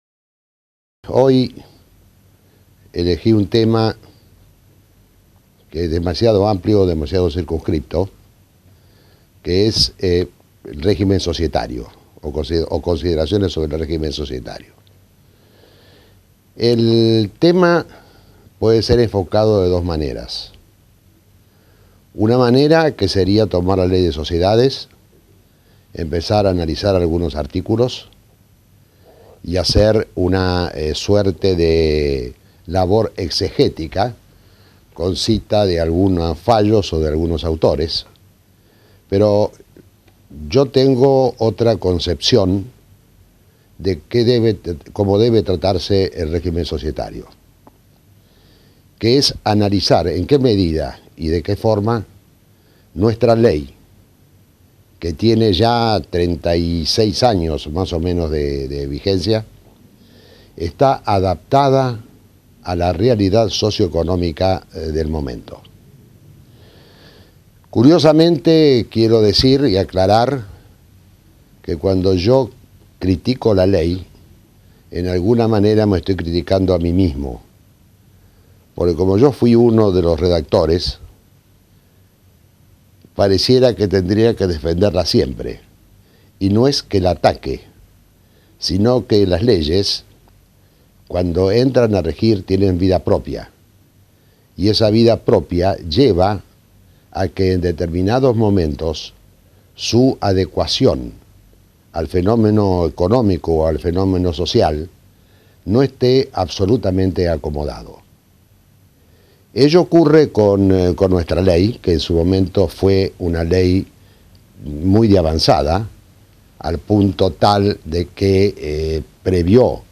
En esta clase analiza en qué medida y de qué forma la ley de sociedades está adaptada a la realidad socio-económica del momento.